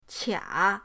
qia3.mp3